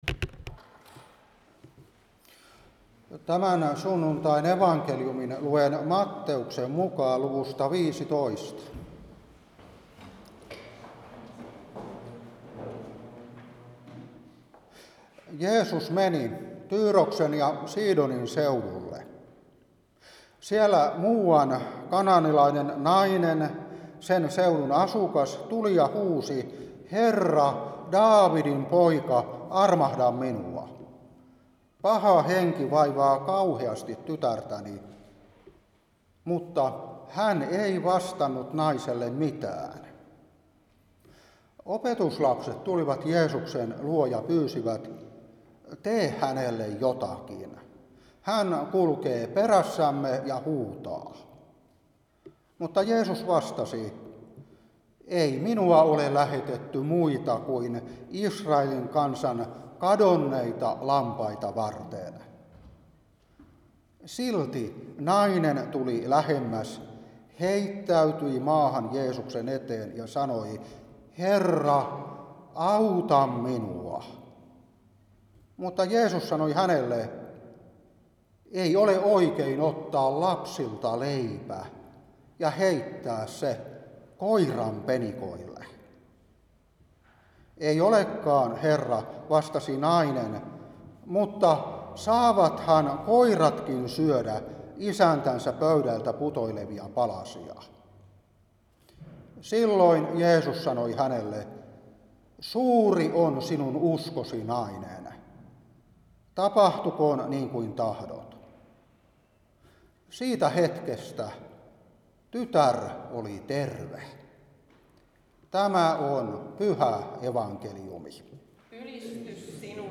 Saarna 2025-3.